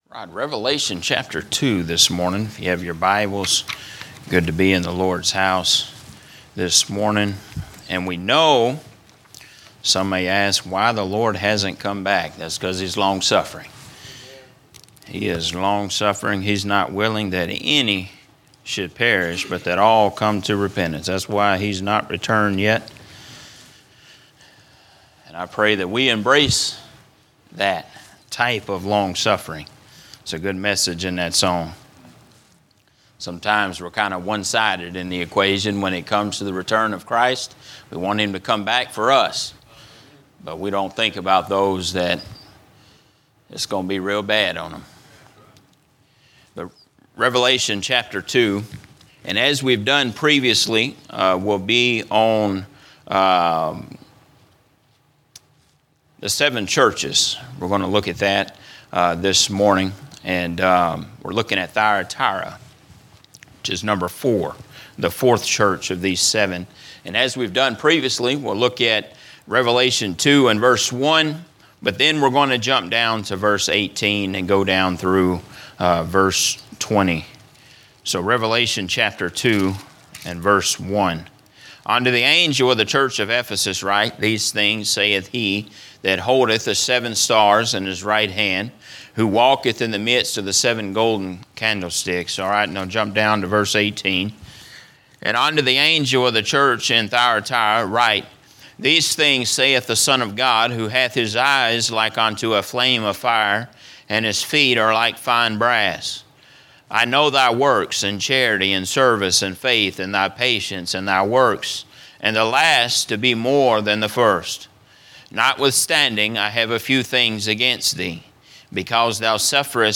A message from the series "General Preaching." A look at the six trials of Jesus leading up to His crucifixion